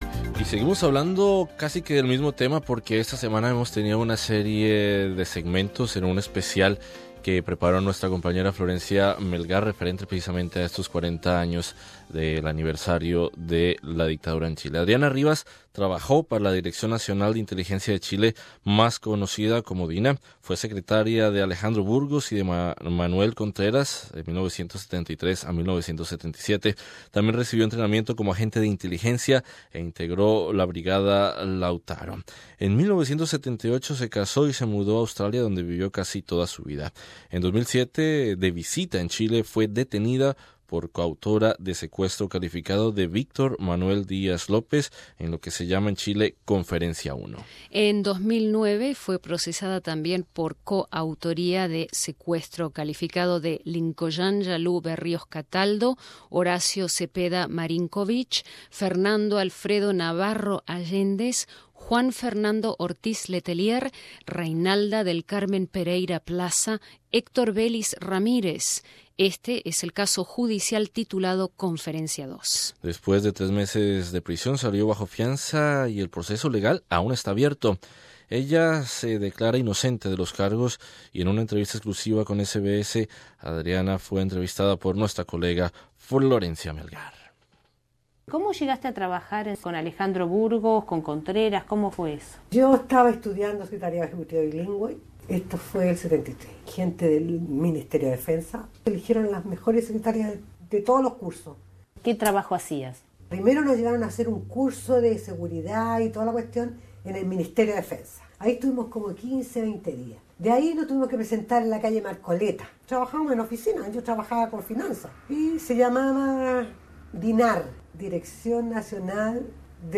El otro 11 de Septiembre: Entrevista